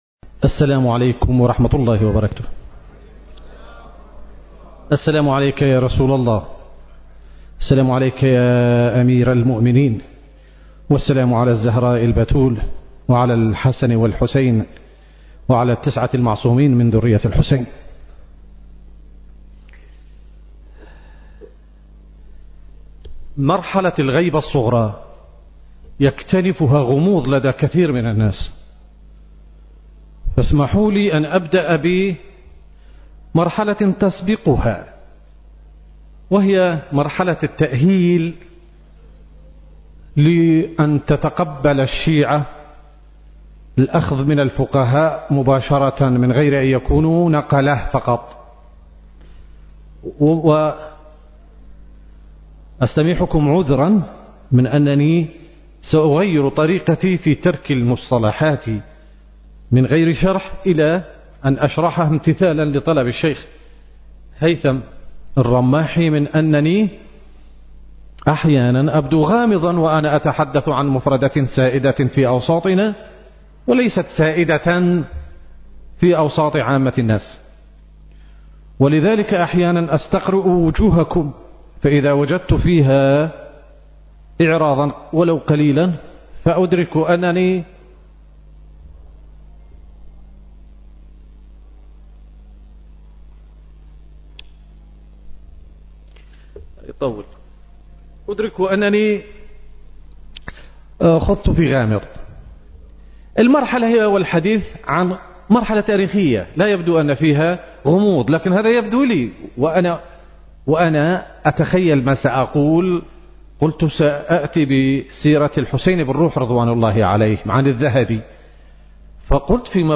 المكان: مسجد وحسينية آل الرسول / بغداد التاريخ: 19/ شعبان/ 1445 للهجرة - مهرجان ذخيرة الأنبياء (عليهم السلام)